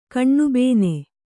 ♪ kaṇṇubēne